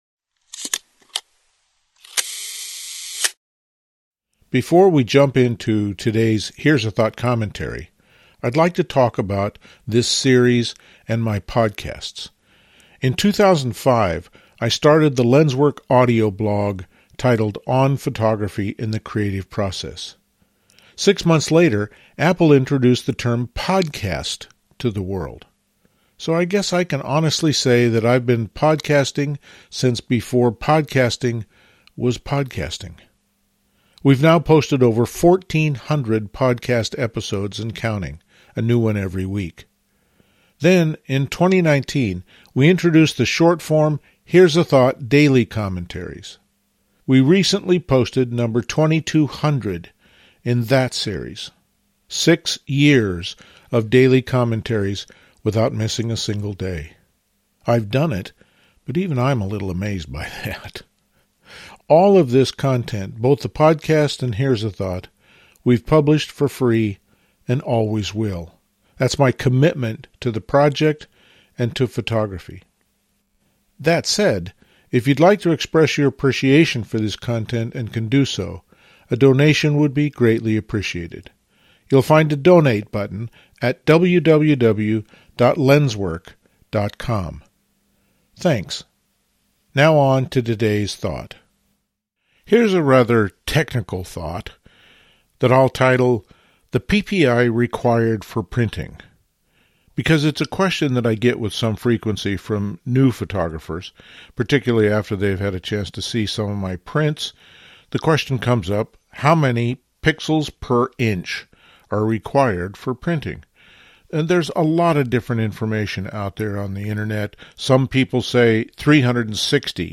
Included in this RSS Feed are the LensWork Podcasts — posted weekly, typically 10-20 minutes exploring a topic a bit more deeply — and our almost daily Here's a thought… audios (extracted from the videos.)